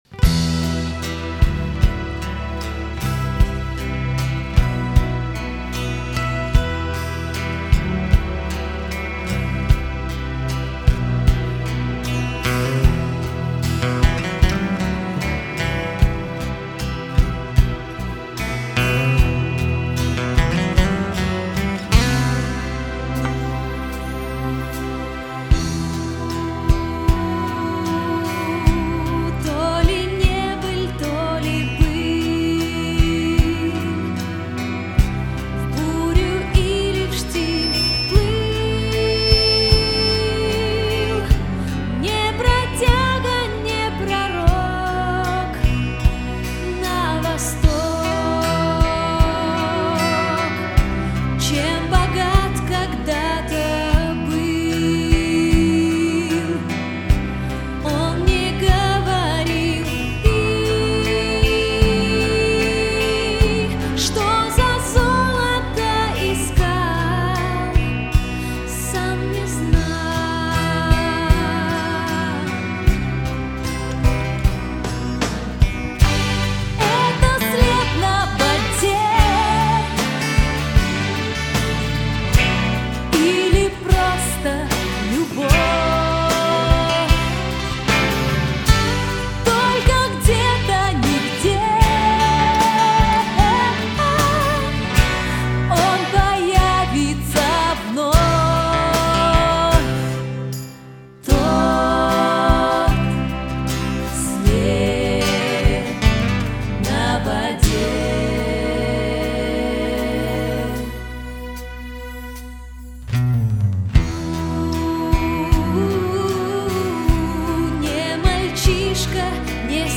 свист микрофона на 35 сек-до